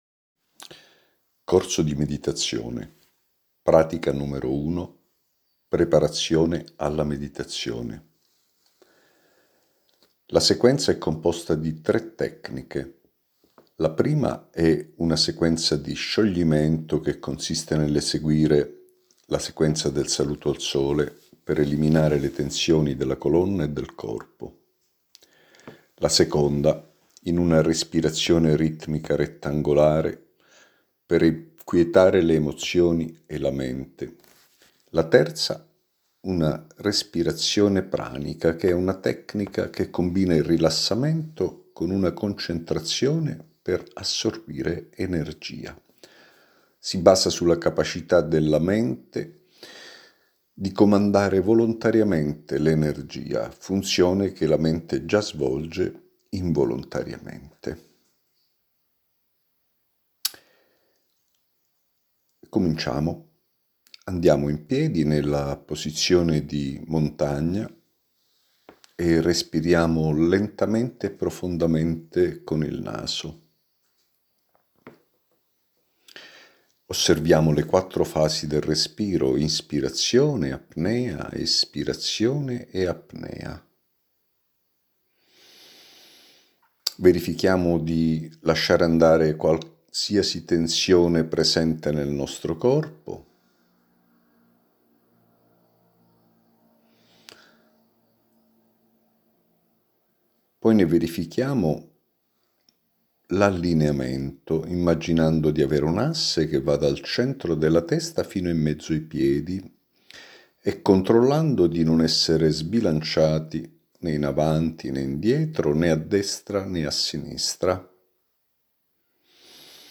Corso di meditazione – Lezione 1